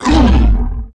nemesis_hurt_02.mp3